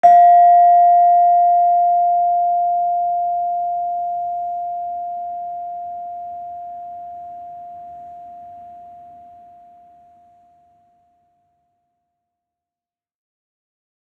Gender-2-F4-f.wav